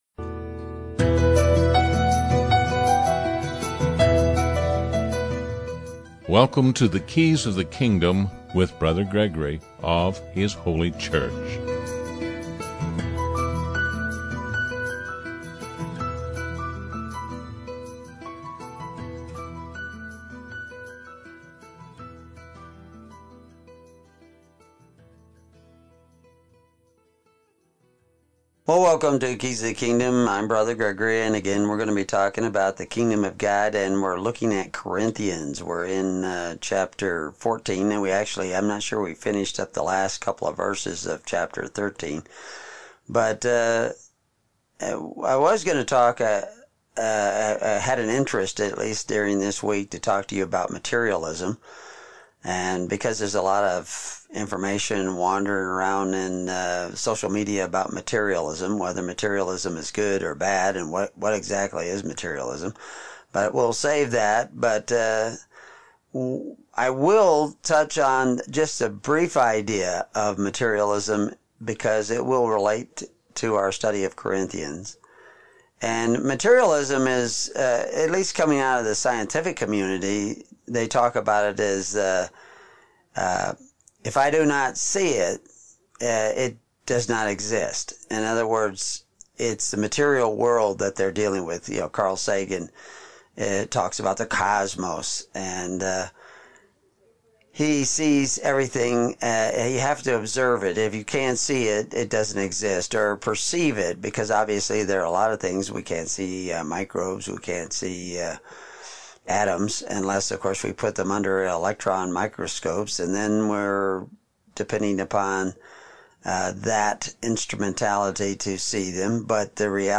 Comments Download Recording 1 Corinthians 13 and 14 broadcast We are to "Follow after charity " and not the covetous practices of the world and it's systems of Corban , which was legal charity , which is idolatry .